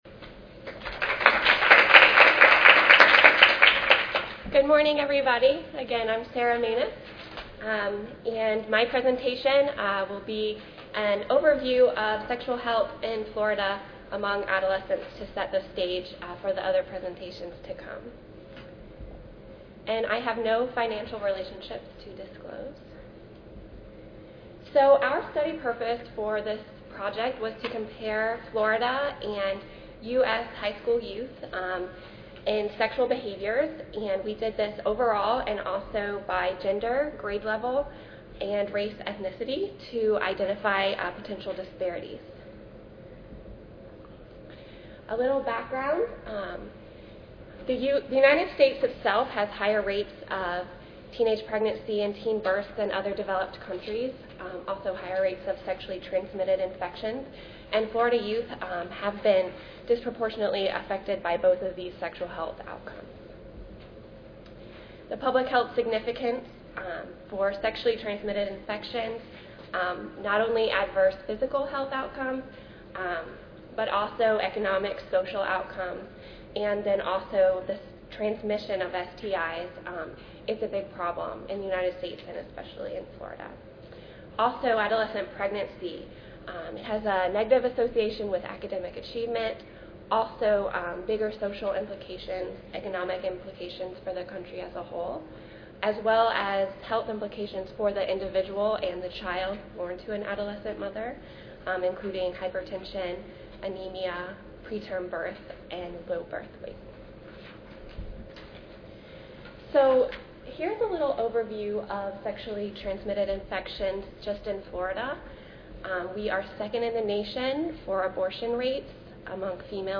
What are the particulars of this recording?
141st APHA Annual Meeting and Exposition